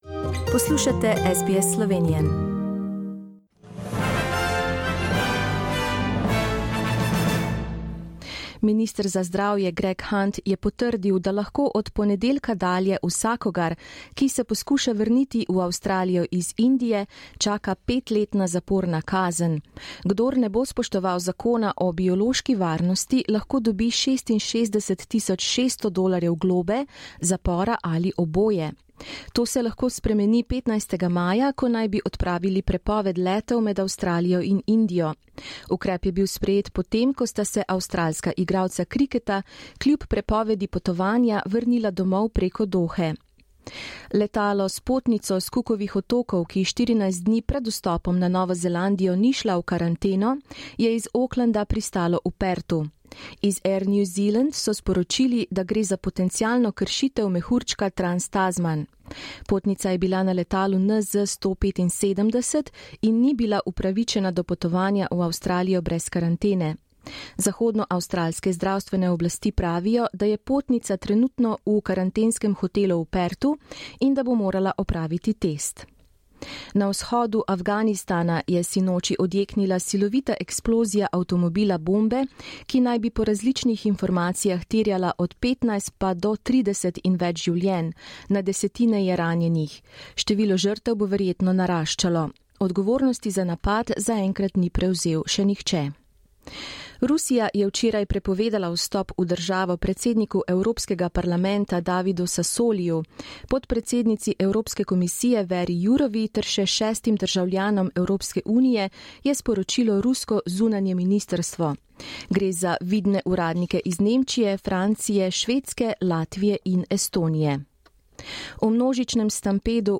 Listen to the latest news headlines in Australia from SBS Slovenian radio.